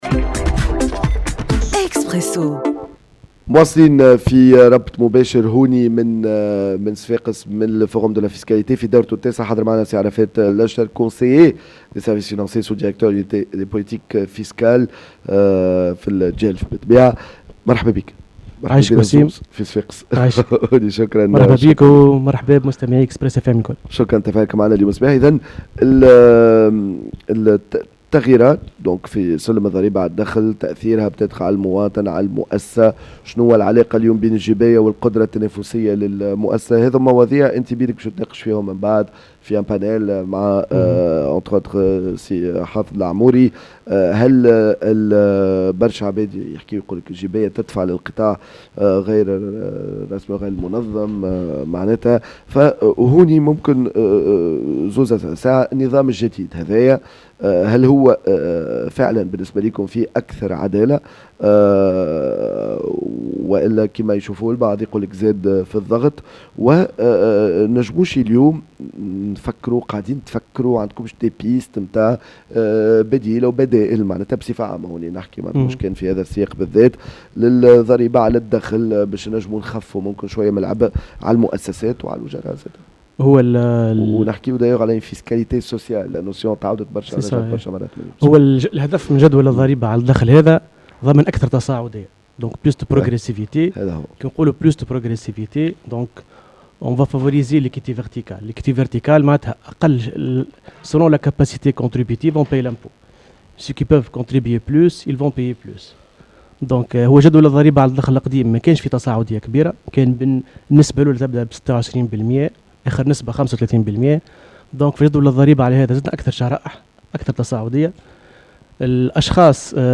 مباشرة من منتدى الجباية في نسخته التاسعة